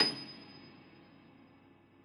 53g-pno27-D6.wav